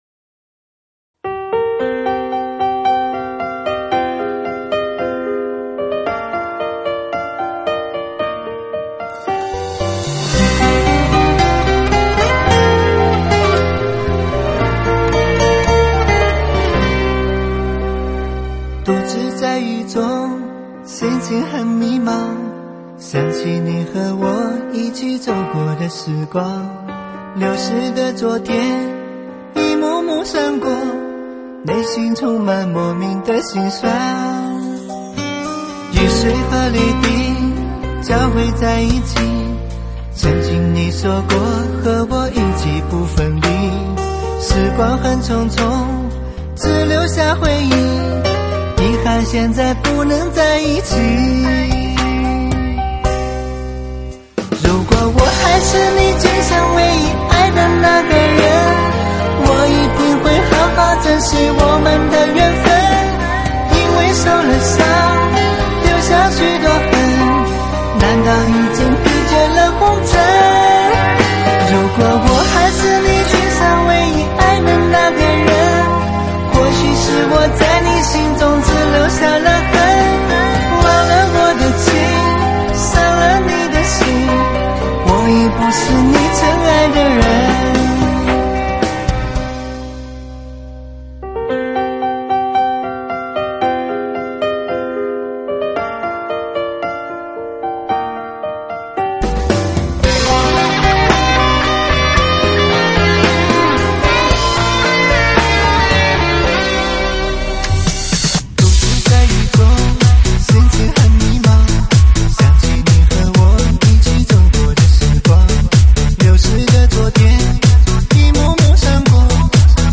全伤感中文CLUB串烧